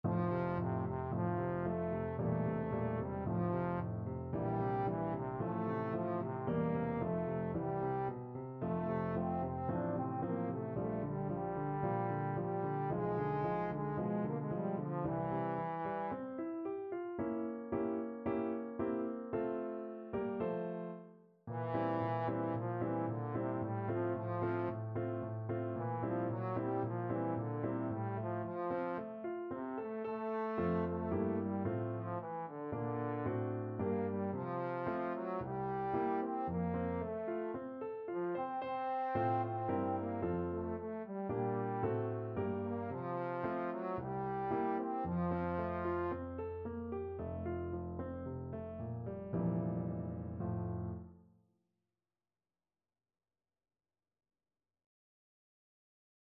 Trombone
F major (Sounding Pitch) (View more F major Music for Trombone )
~ = 56 Affettuoso
2/4 (View more 2/4 Music)
Classical (View more Classical Trombone Music)